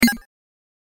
button-23.mp3